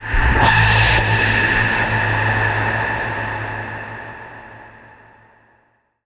VEC3 FX Athmosphere 06.wav